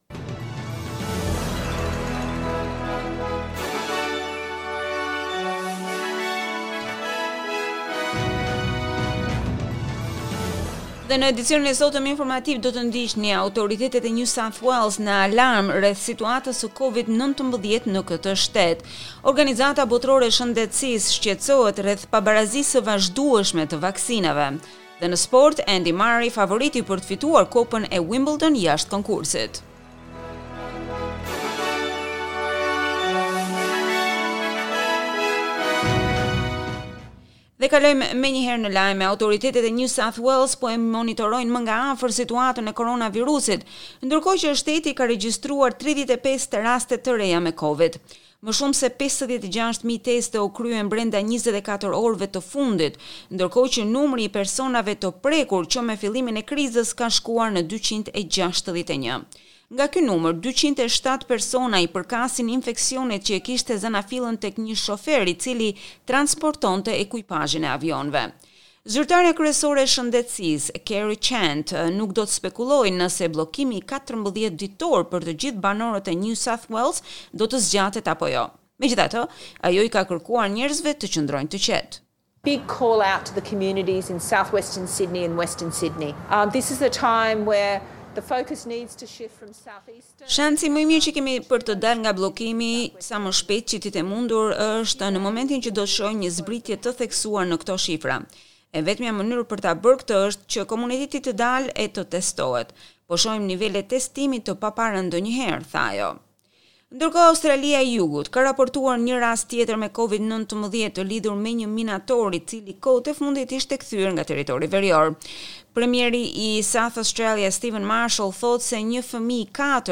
SBS News Bulletin in Albanian - 3 July 2021